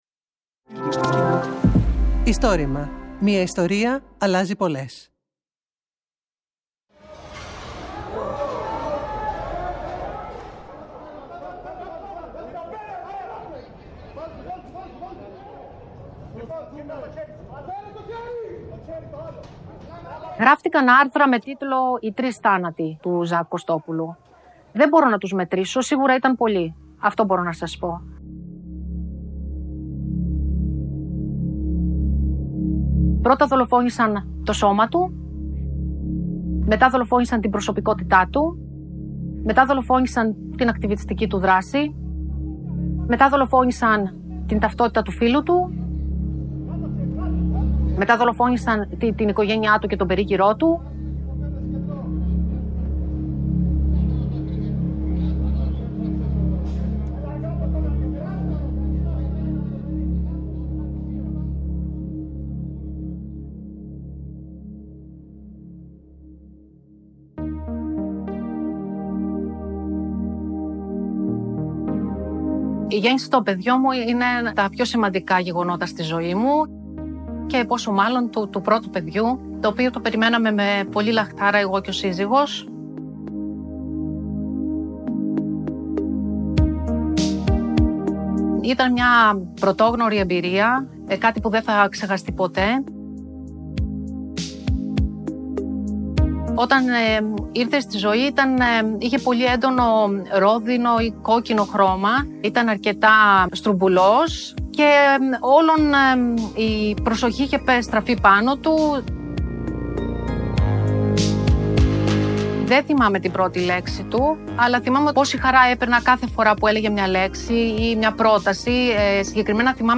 Το Istorima είναι το μεγαλύτερο έργο καταγραφής και διάσωσης προφορικών ιστοριών της Ελλάδας.